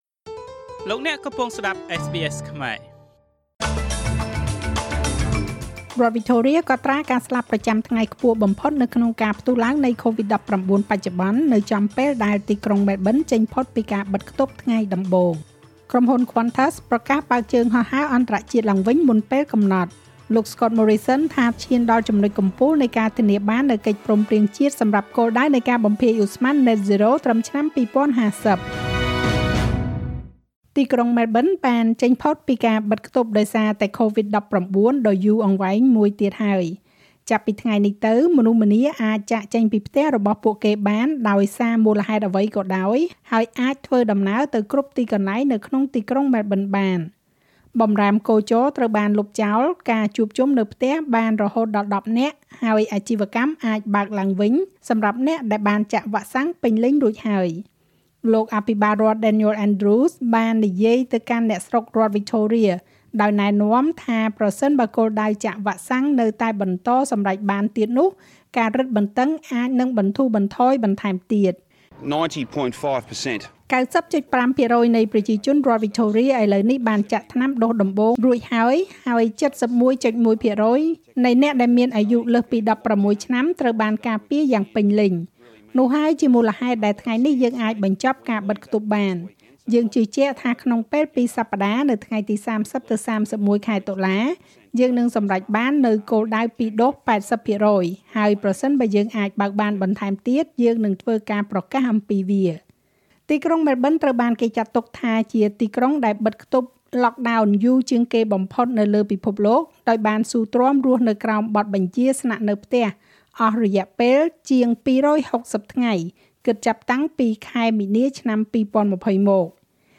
នាទីព័ត៌មានរបស់SBSខ្មែរ សម្រាប់ថ្ងៃសុក្រ ទី២២ ខែតុលា ឆ្នាំ២០២១